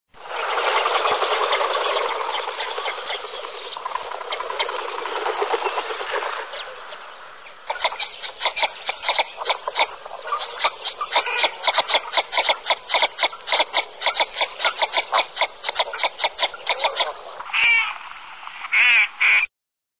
Canto del pato cuchara
cantoPatoCuchara.mp3